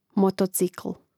motocìkl motocikl